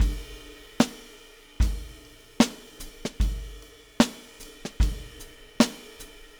Weathered Beat Crash 02.wav